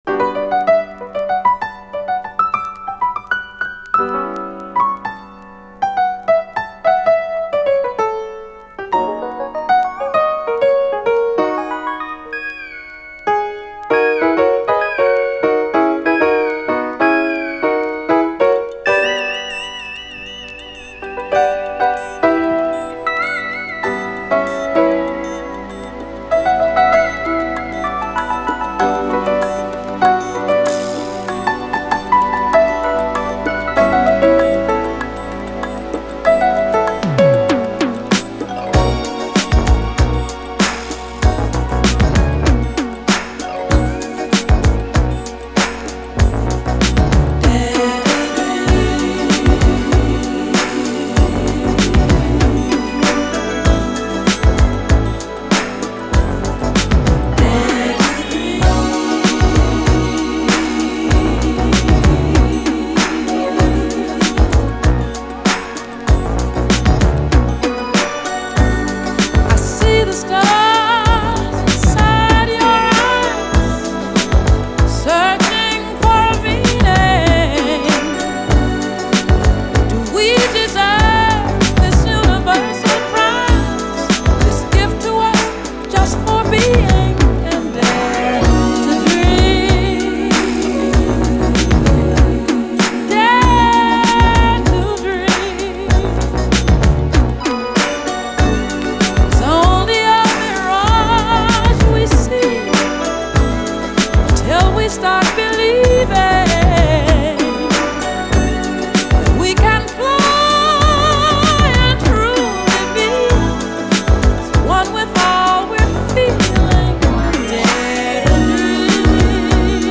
DISCO
最高バレアリック・メロウ・シンセ・ブギー！
メイン・ヴォーカルなし、ブリブリのベースとエレガントなピアノ・アレンジが光るインスト・ミックス「